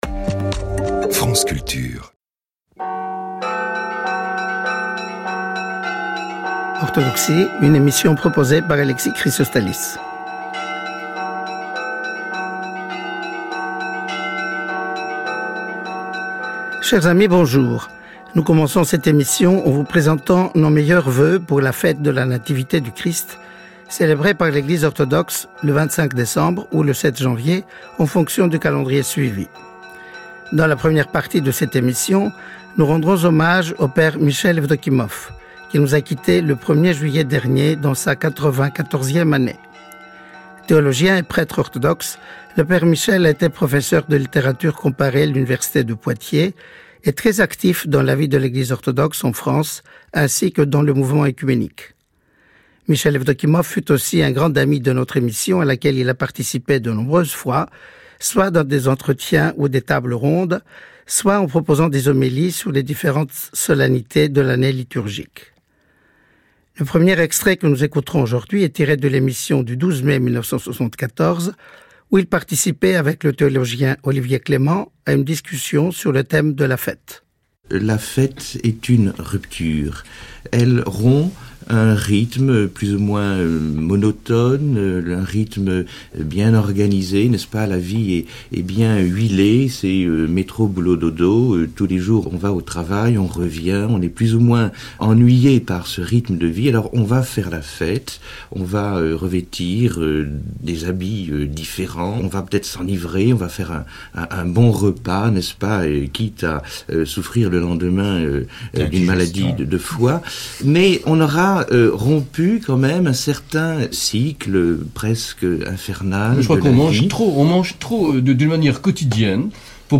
archive 1 : extrait de l’émission du 12 mai 1974 où il participait avec le théologien Olivier Clément à une discussion sur le thème de la « fête ».
archive 2 : extrait de l’émission du 25 décembre 2001, dans laquelle il avait prononcé une homélie sur le sens de la fête de Noël. 2e partie : message de Noël du métropolite Dimitrios (Ploumis), président de l’Assemblée des évêques orthodoxes de France.